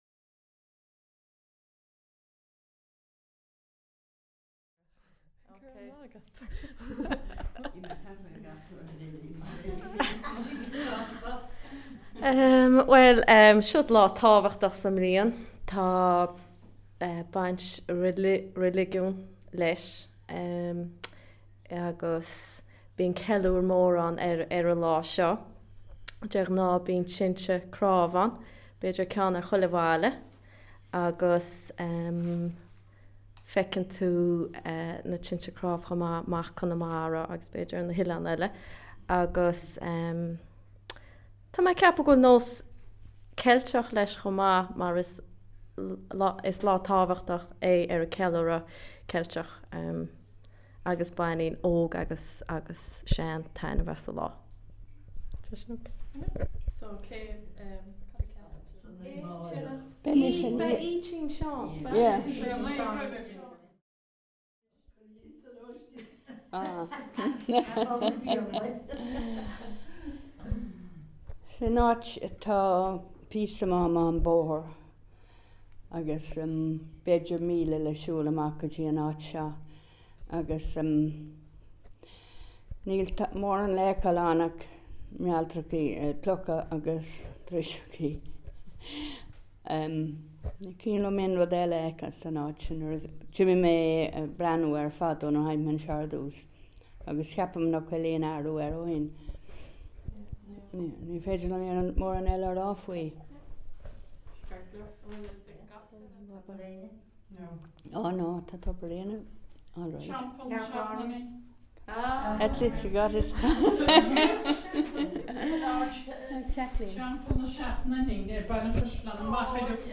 Audio Installation